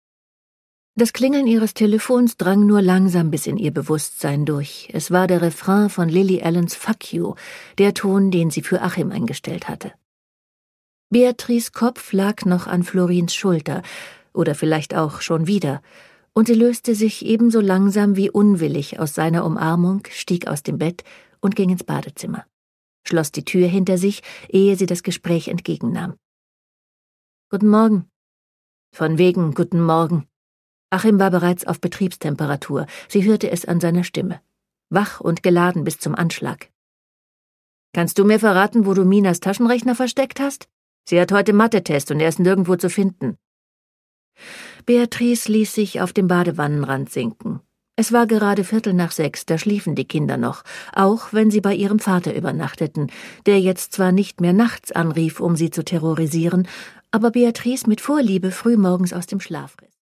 Produkttyp: Hörbuch-Download
Gelesen von: Andrea Sawatzki